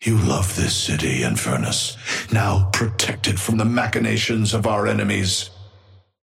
Amber hand voice line - You love this city, Infernus.
Patron_male_ally_inferno_start_04.mp3